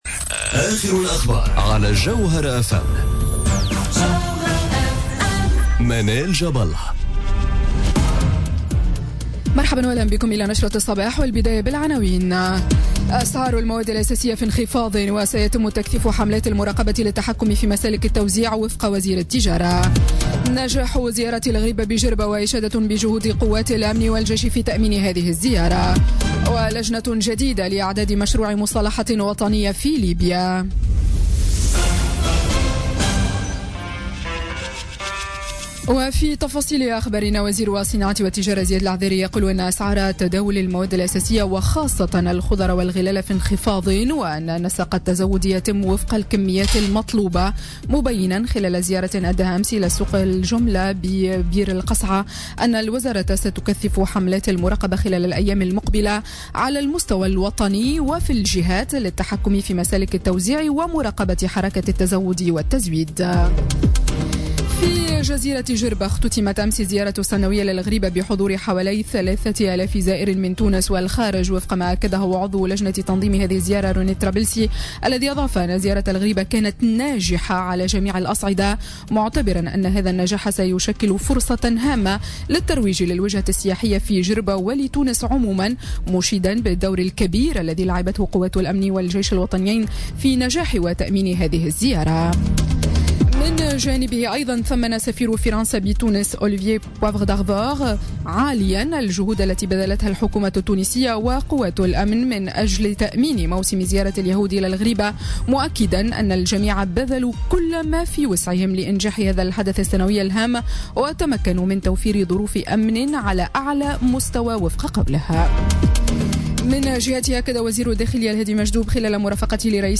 نشرة أخبار السابعة صباحا ليوم الإثنين 15 ماي 2017